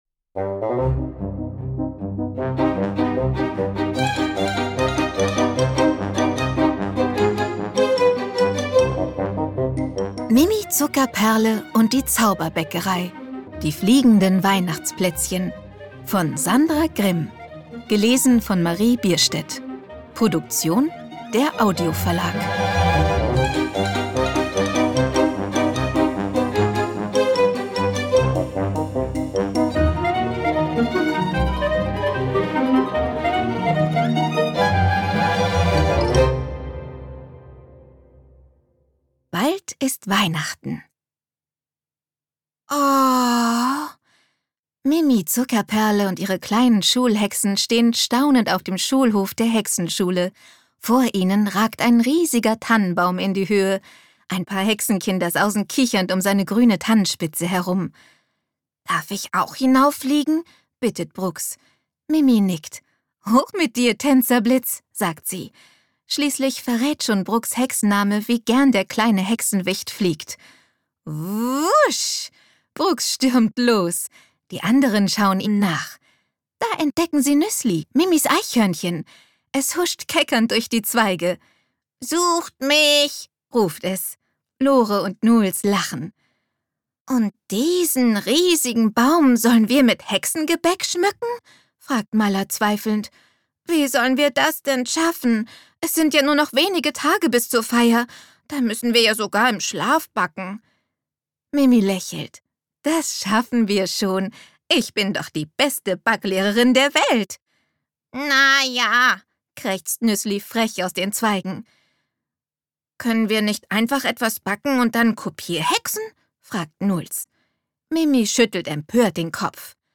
Ungekürzte Lesung mit Musik